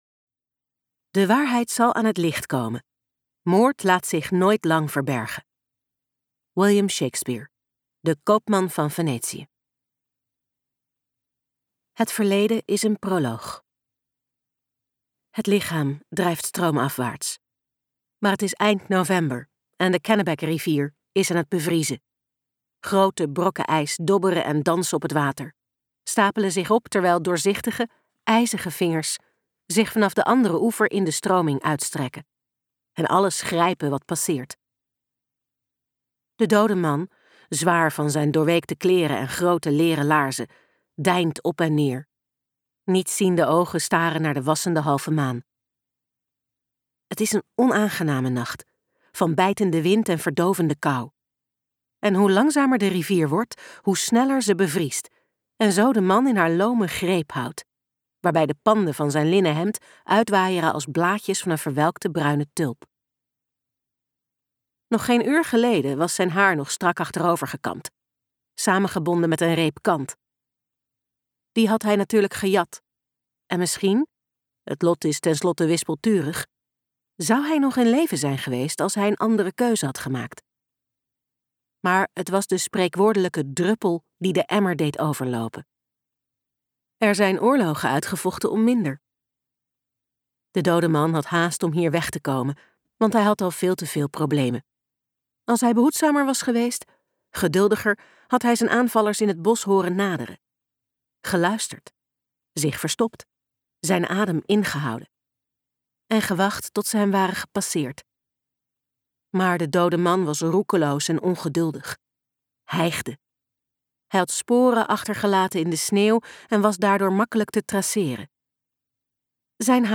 KokBoekencentrum | De rivier luisterboek